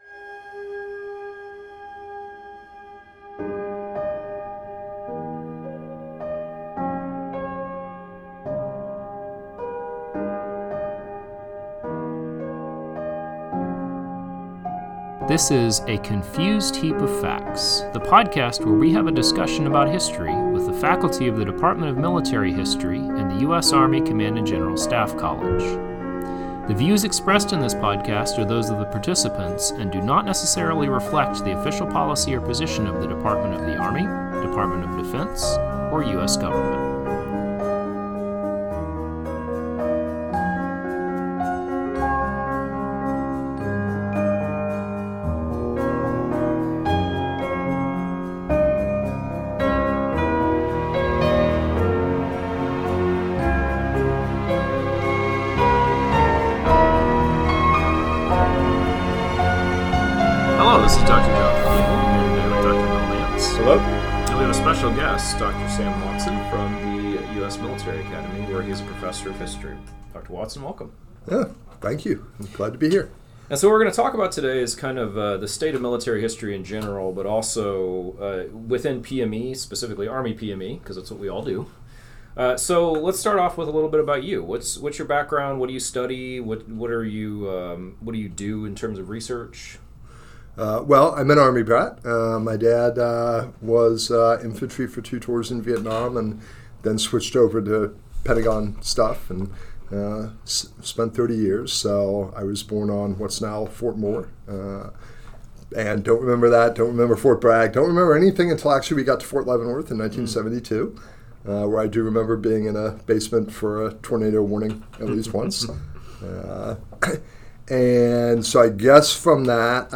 They have a wide-ranging discussion on officers in the early US republic and the state of military history today.